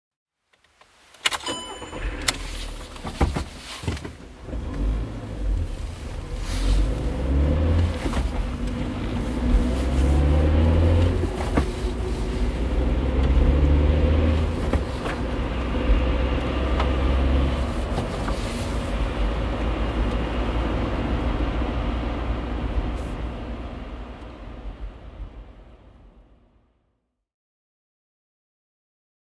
Download Car sound effect for free.
Car